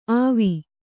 ♪ āvi